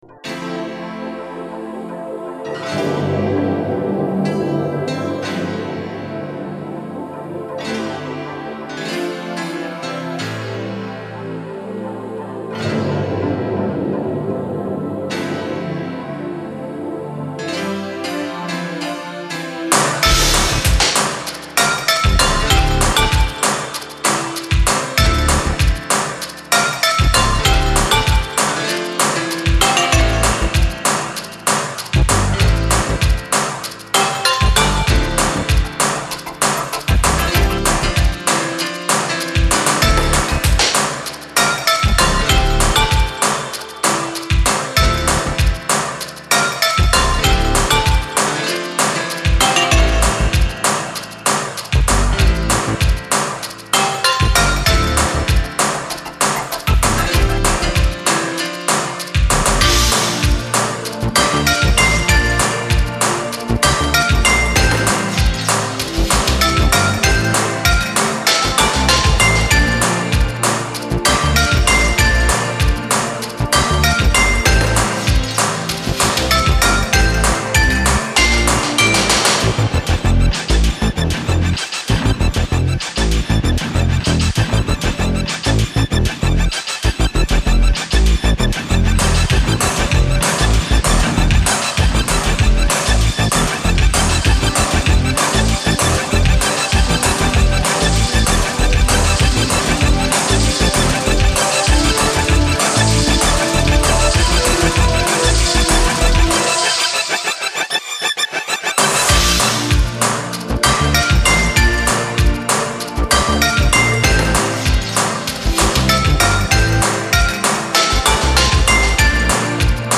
他们的音乐充满图画色彩，清新静怡、精致脱俗，是现代都市人安抚、净化心灵的良药，有着"治疗音乐”之称。